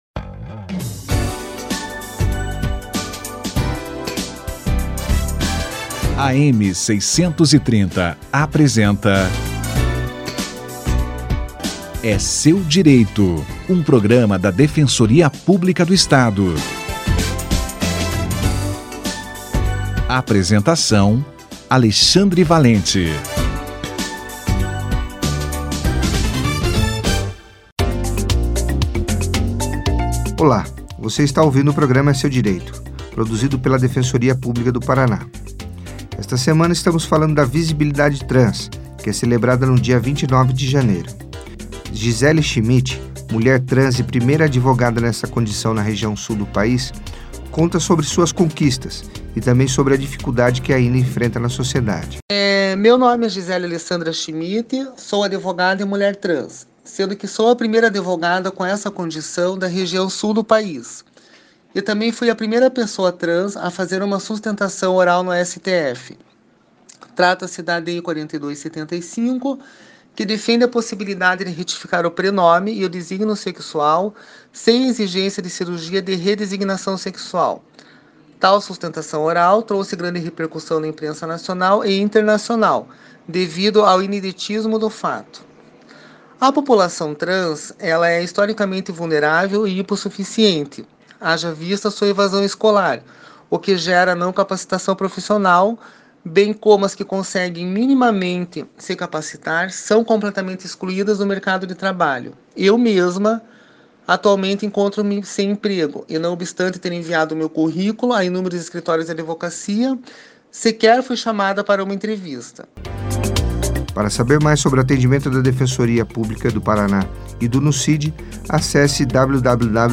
Dificuldade da população trans no mercado de trabalho - entrevista